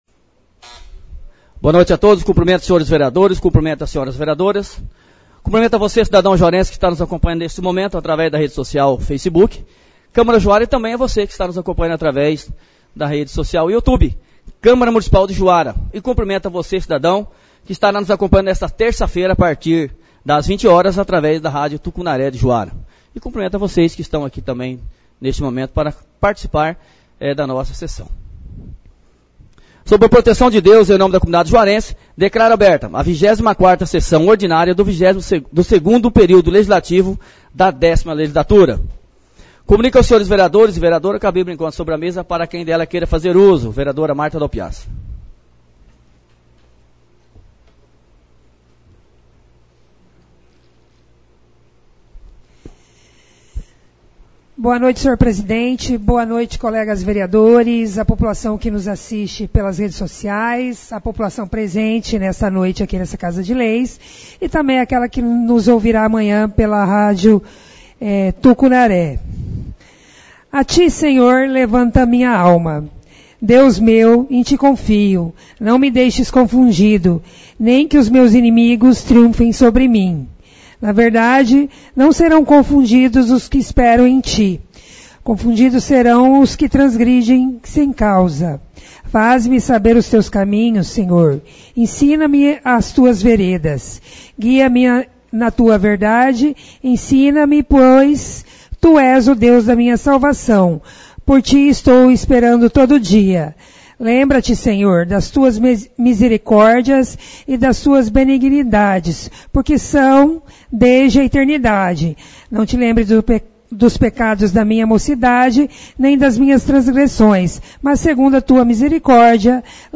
Câmara Municipal de Juara - Sessões do Poder Legislativo - Ano 2022